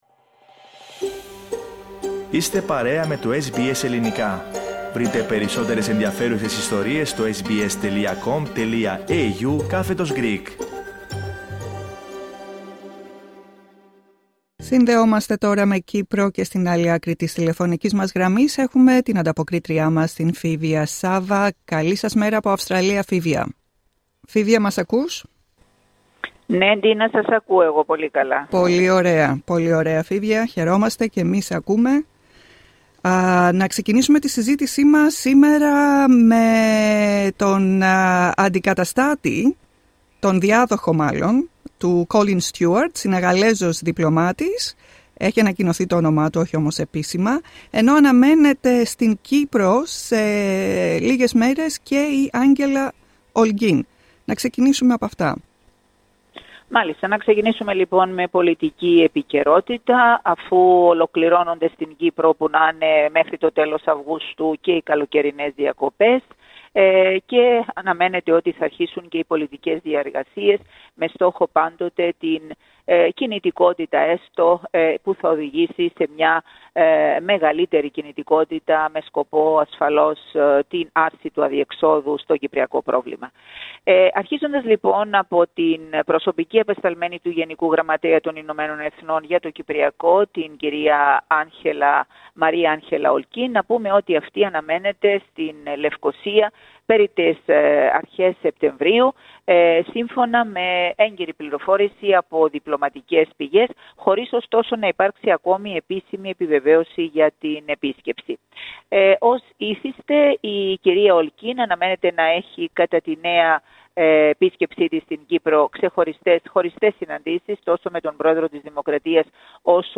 Ακούστε την εβδομαδιαία ανταπόκριση από την Κύπρο